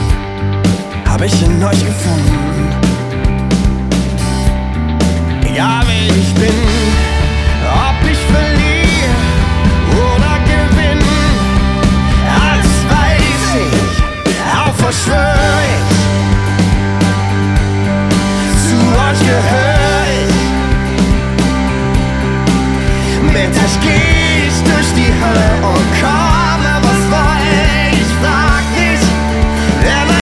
Gesang, Gitarre, Klavier und Backings
eine tief emotionale Atmosphäre